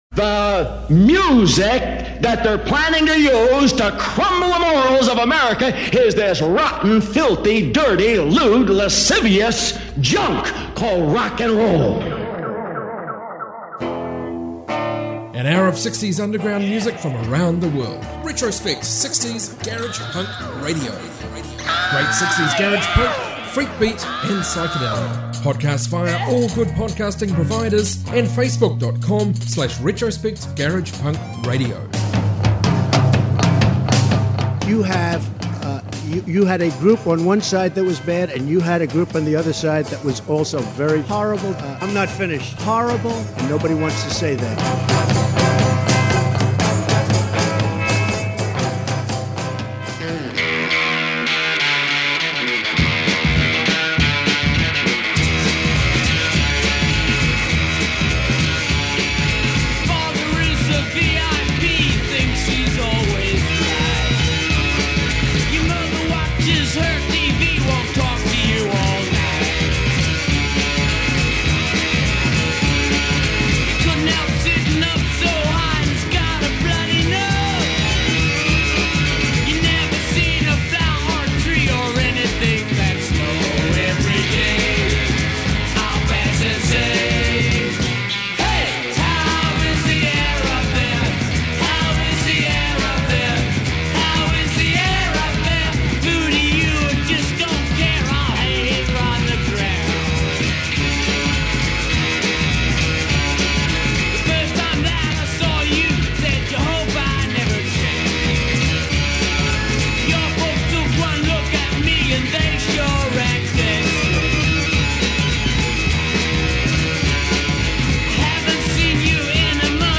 60s garage punk